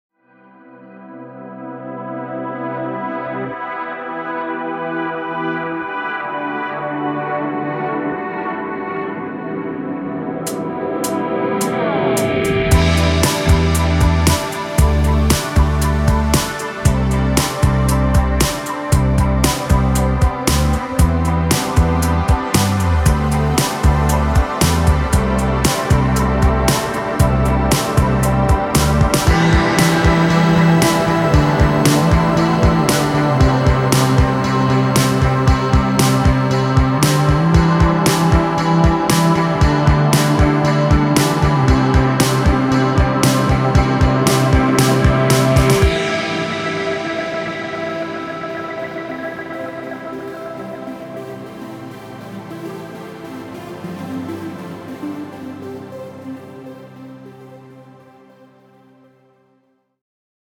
Indie Pop